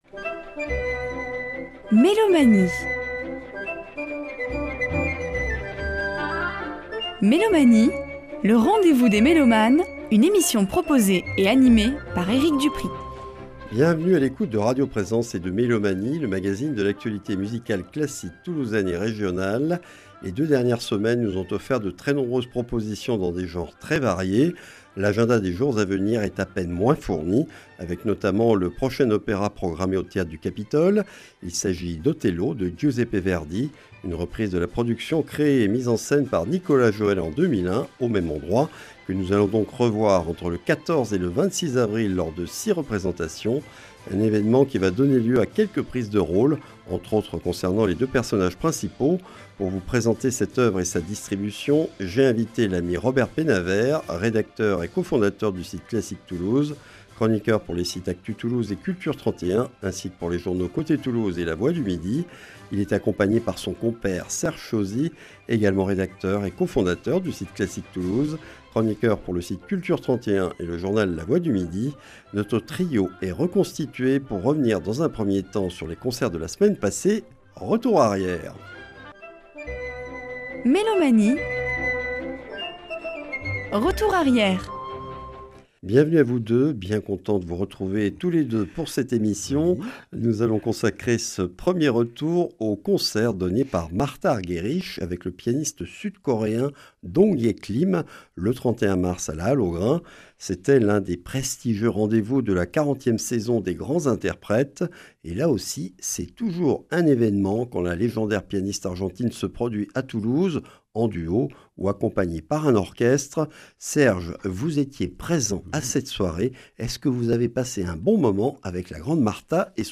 Mélomanie(s) et ses chroniqueurs présentent l’actualité musicale classique de notre région.